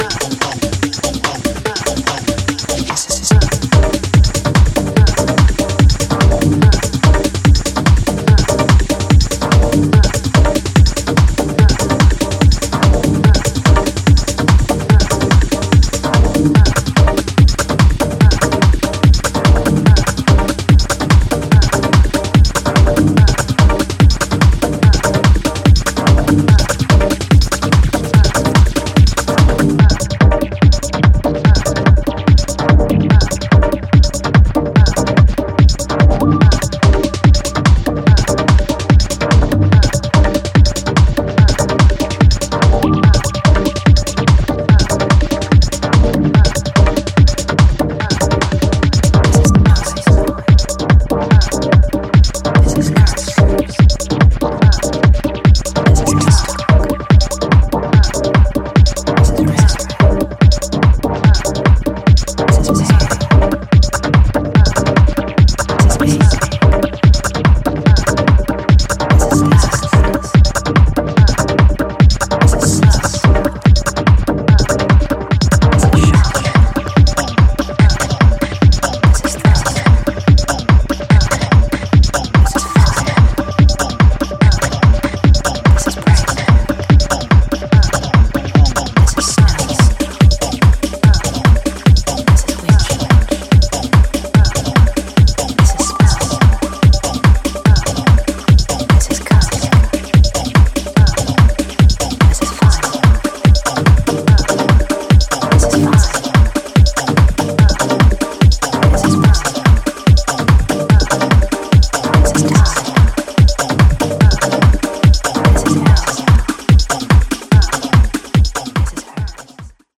全編非常に歯切れの良いハイテンポのグルーヴが走るモダン・テック・ハウスで痛快そのもの。